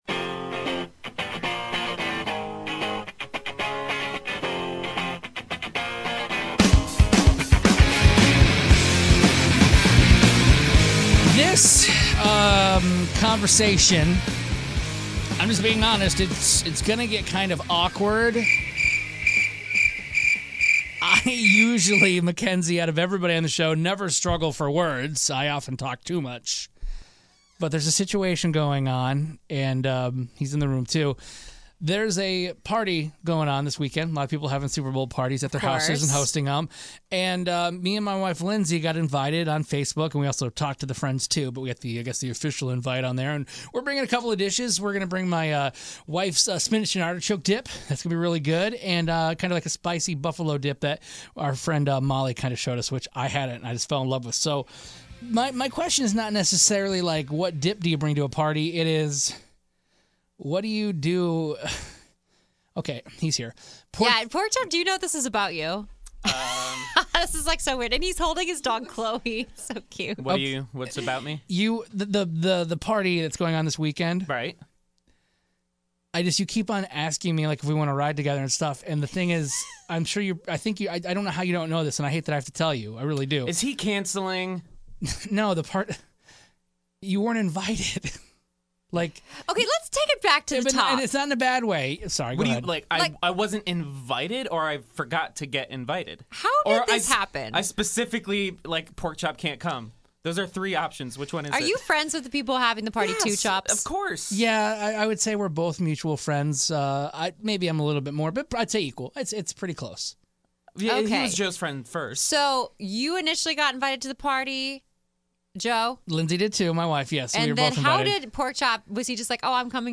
There was some tension in the studio this morning